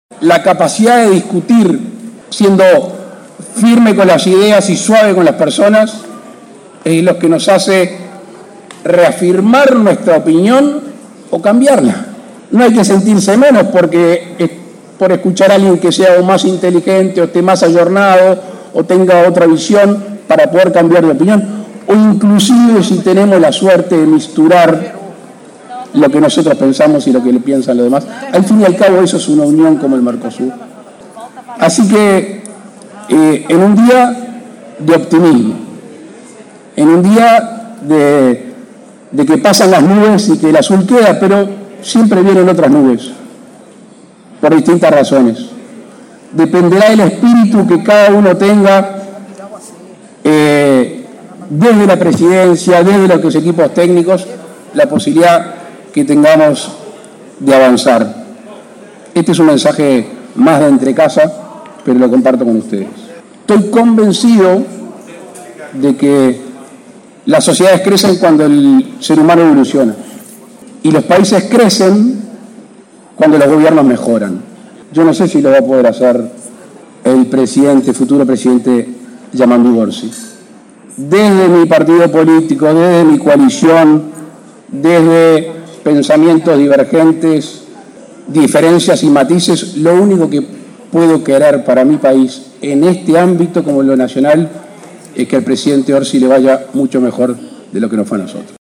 El presidente Lacalle Pou se despidió este viernes de la Cumbre del Mercosur y con el presidente electo Yamandú Orsi a su lado, dio un mensaje dirigido a su sucesor.